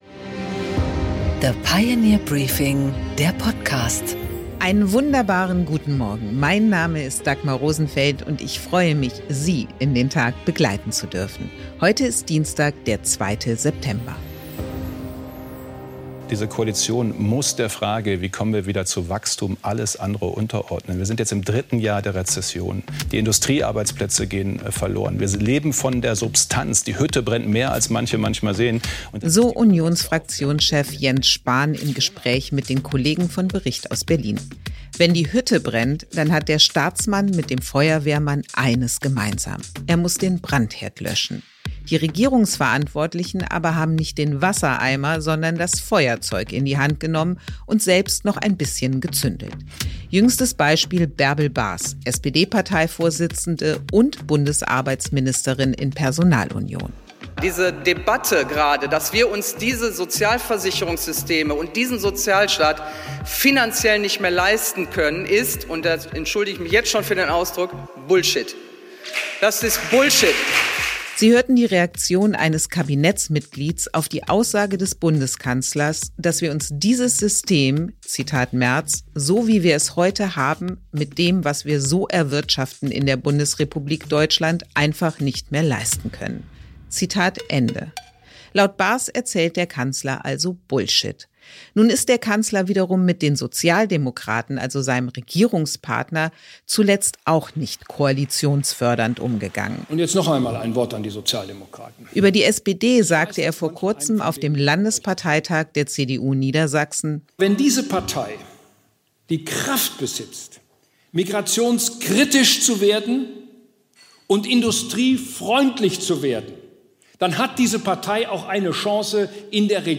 Im Gespräch: Martin Huber